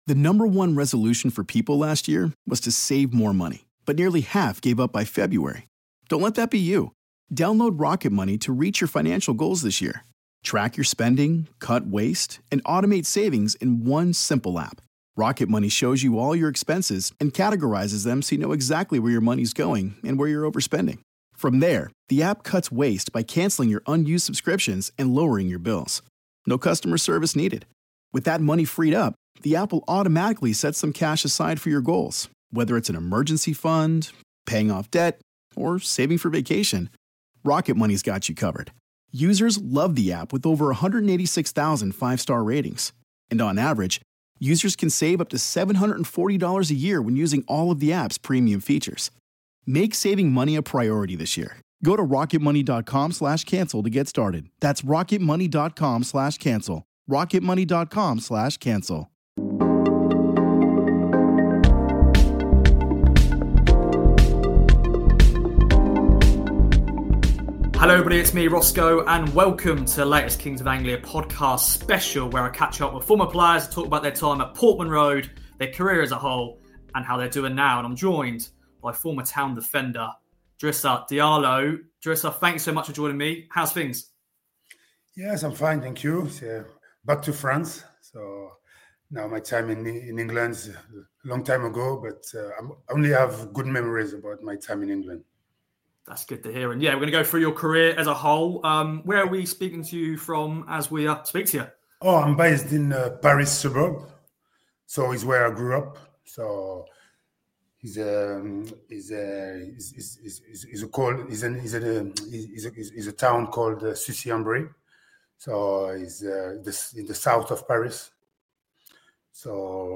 KOA special: Drissa Diallo interview